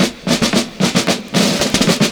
112FILLS09.wav